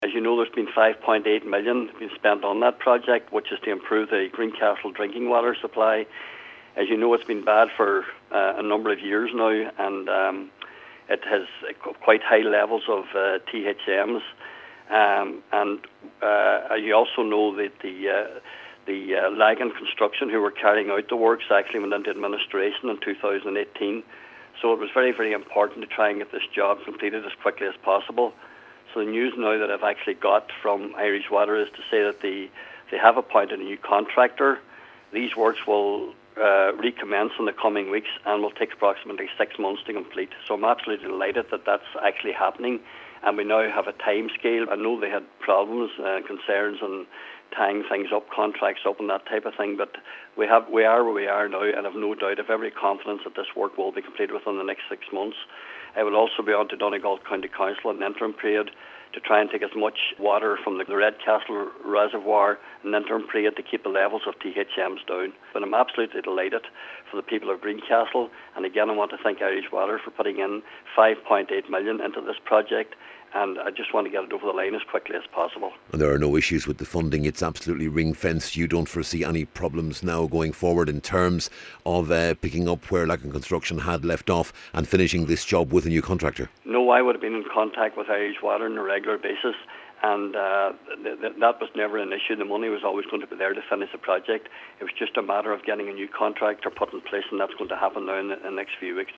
Cllr Martin Farren says it’s a welcome development: